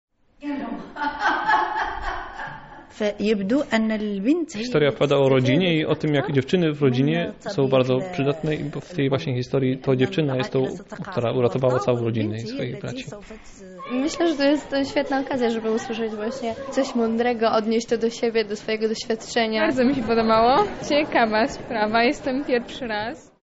jedna z opowiadaczek.